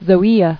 [zo·e·a]